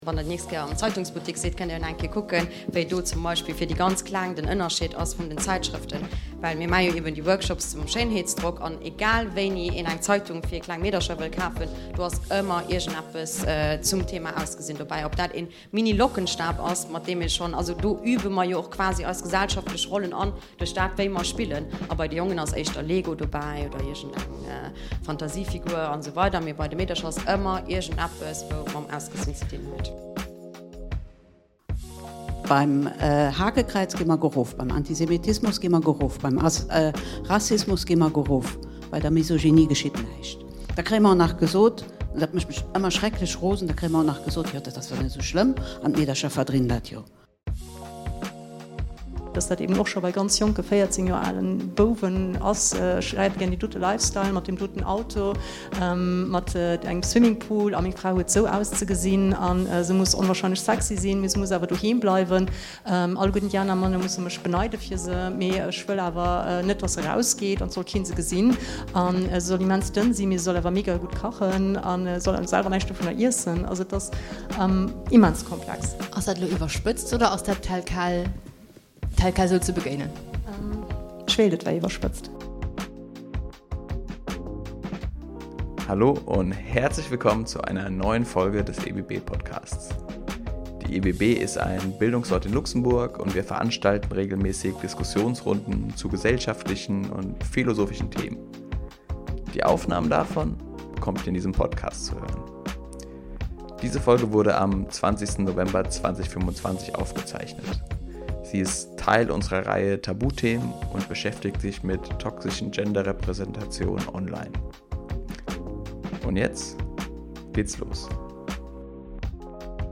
An dëser Table Ronde hu mir eis mat toxesch Genderrepresentatiounen am Internet beschäftegt. Et goung ëm Analys, kritescht Denken, praktesch Hëllef a Präventioun.